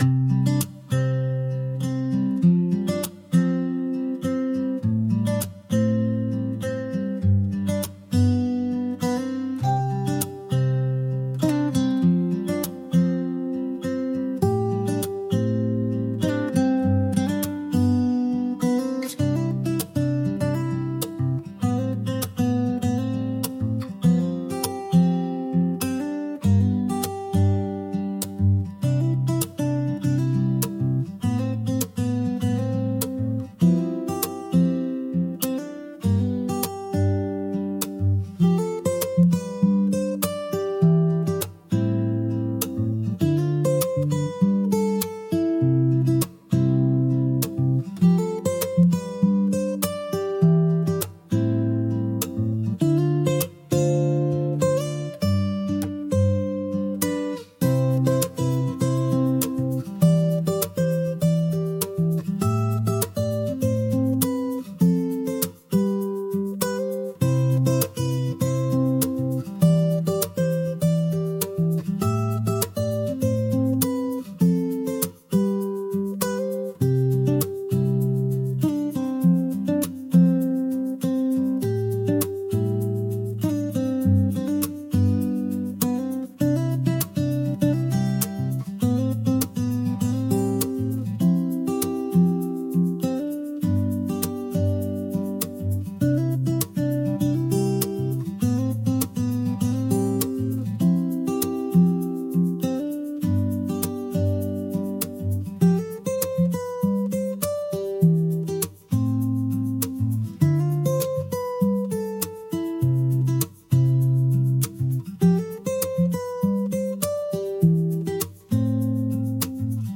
C Major – 100 BPM
Acoustic
Pop
Rnb